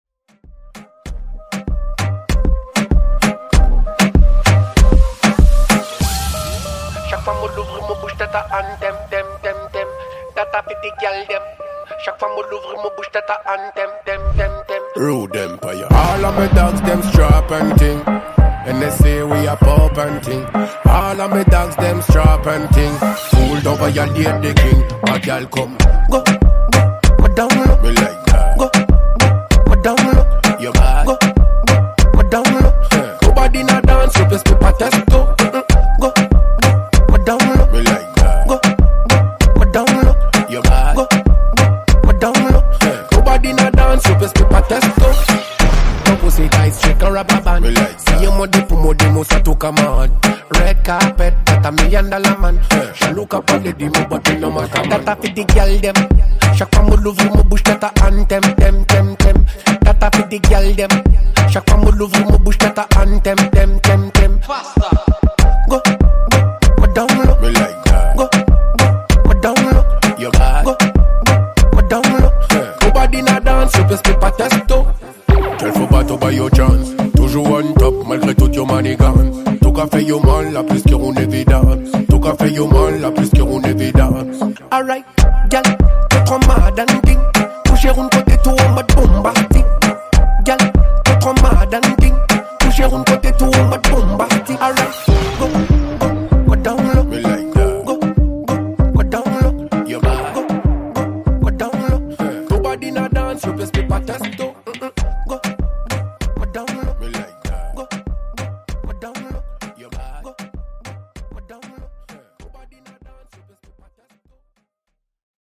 Genre: RE-DRUM Version: Clean BPM: 92 Time